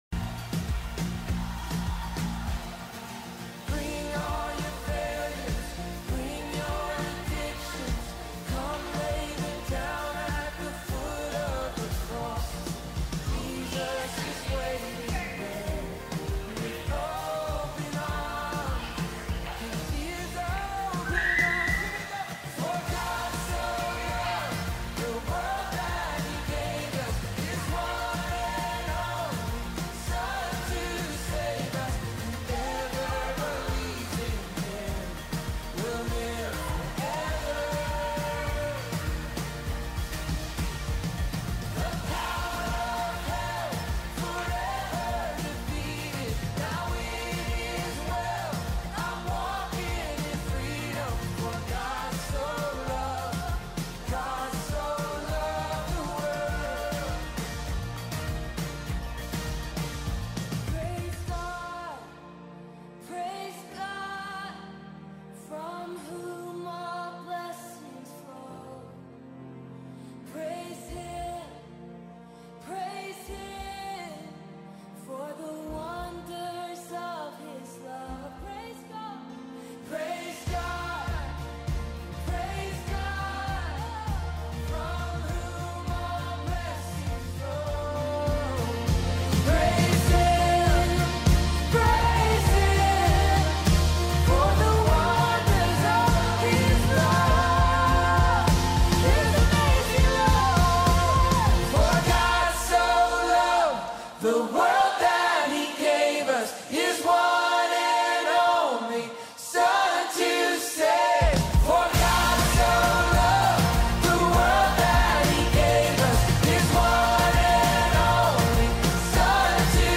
Sermon Audio 11.15.20 - C3 Magnolia